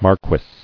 [mar·quess]